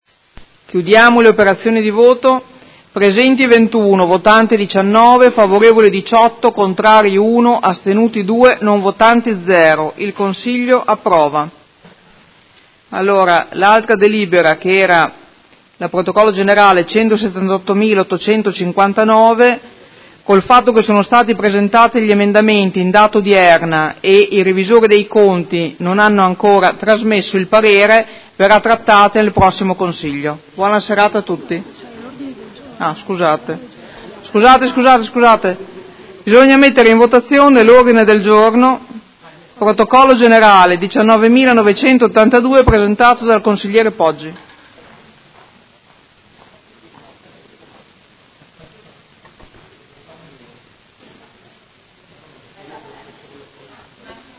Presidentessa — Sito Audio Consiglio Comunale